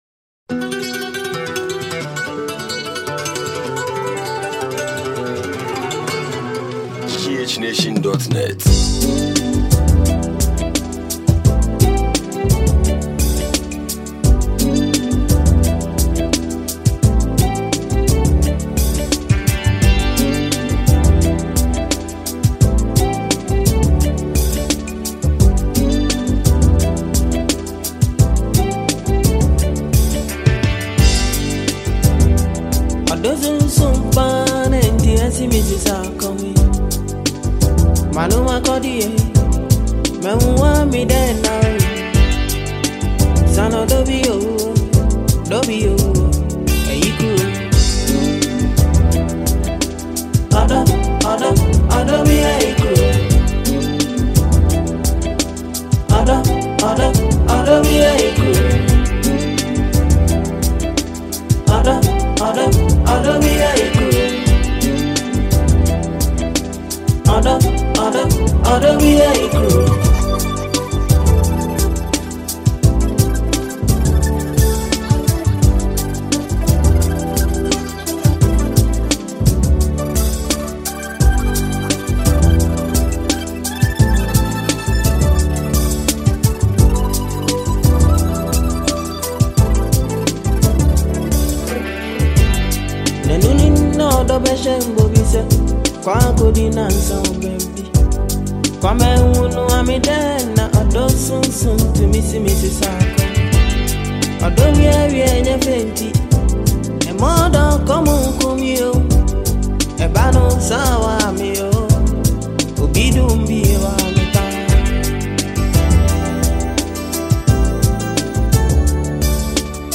an old classical song